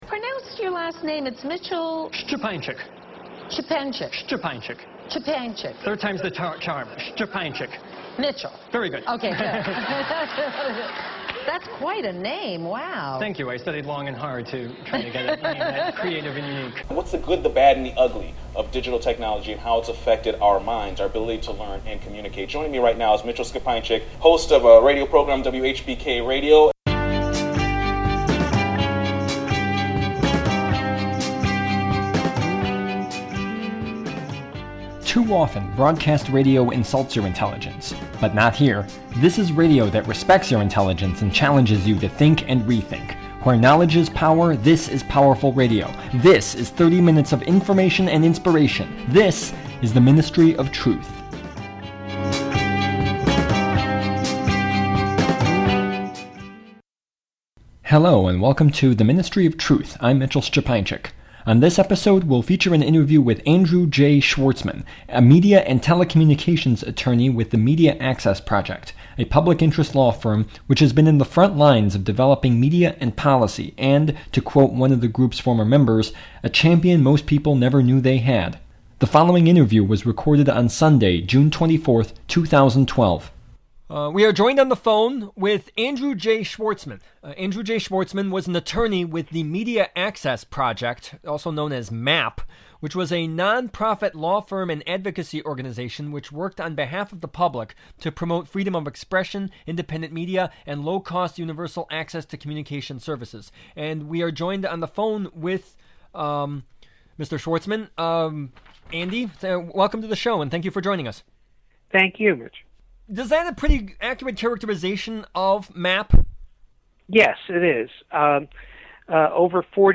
The Ministry of Truth: Interview